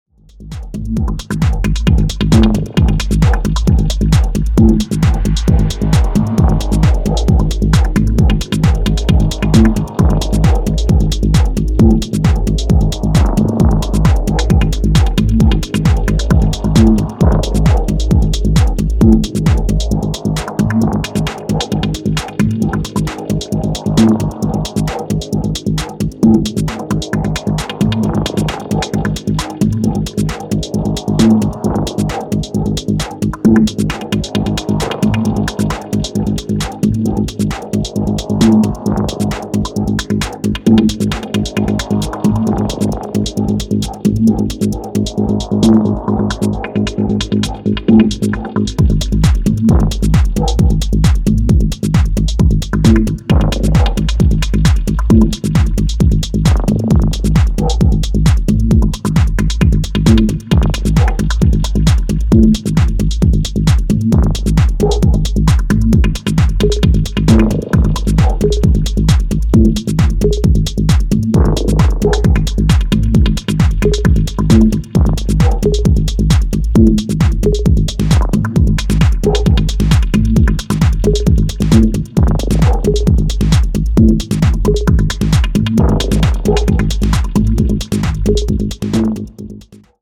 圧の強いEBMテイストを滲ませたヘヴィサイケデリックチューン